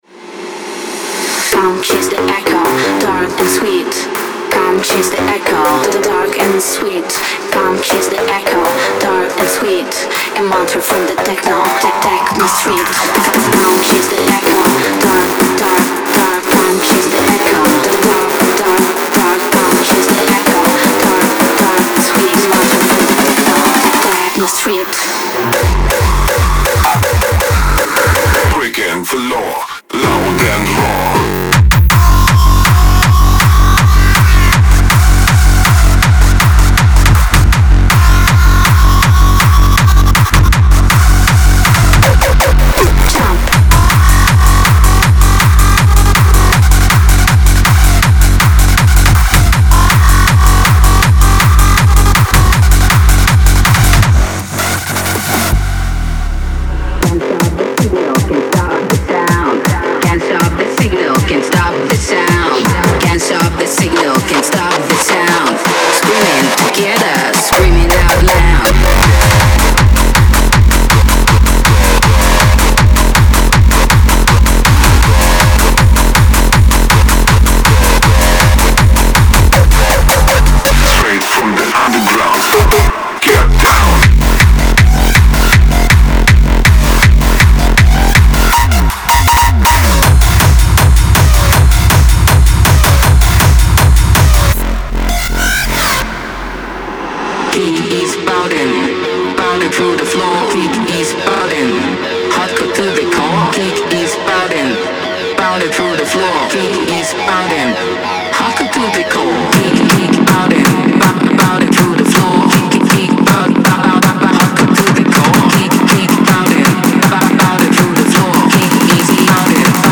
強烈に攻撃的なキック、鋭く突き刺さるスクリーチ、そして意識を揺さぶるアシッドラインに満ちたドロップが特徴です。
デモサウンドはコチラ↓
Genre:Hard Dance
160 BPM